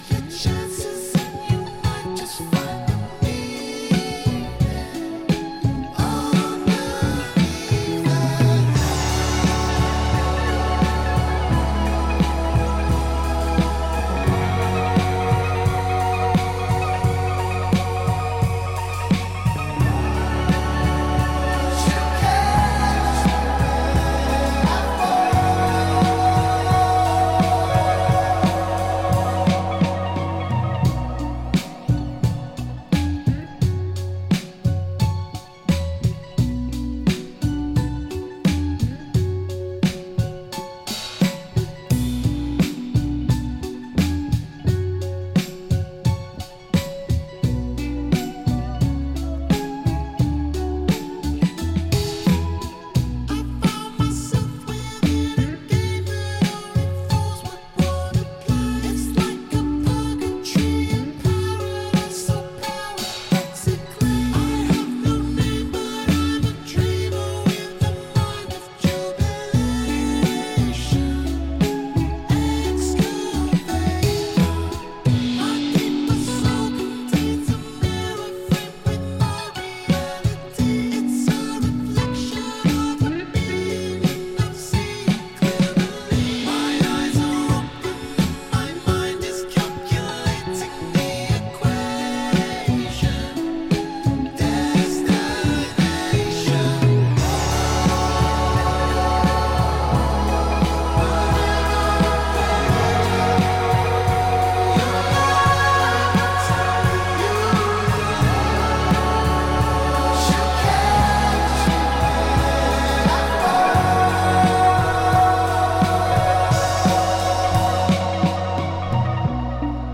Funk, Psychedelic, Soul